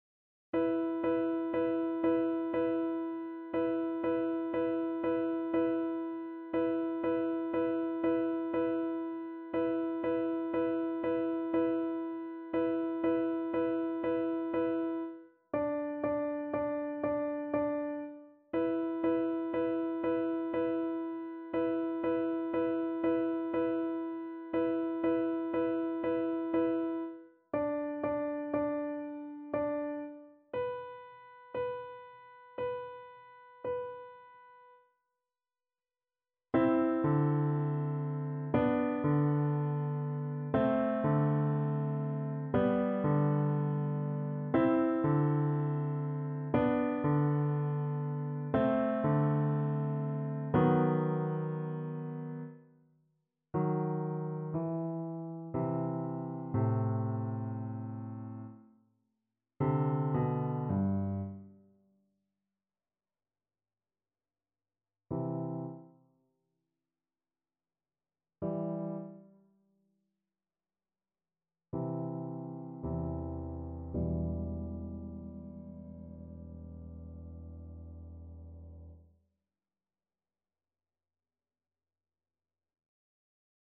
3/4 (View more 3/4 Music)
Andante sostenuto =60
Classical (View more Classical Viola Music)